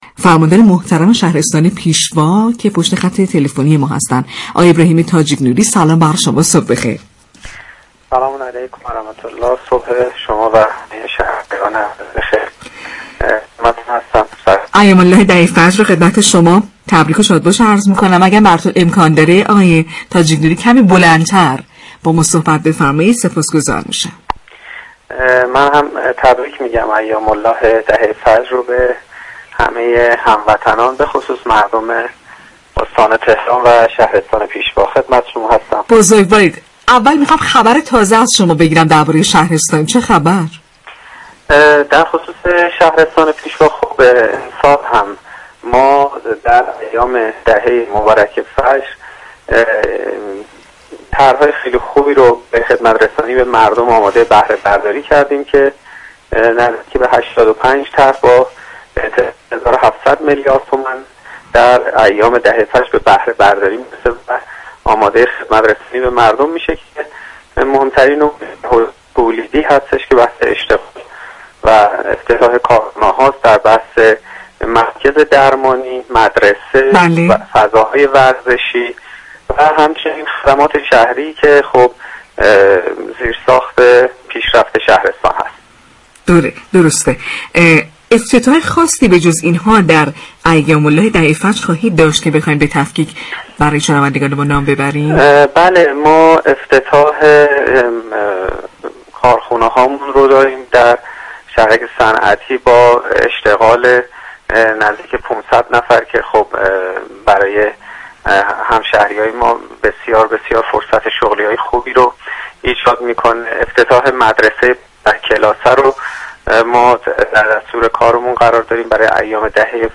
به گزارش پایگاه اطلاع رسانی رادیو تهران، ابراهیم تاجیك‌نوری فرماندار پیشوا در گفت و گو با «شهر آفتاب» اظهار داشت: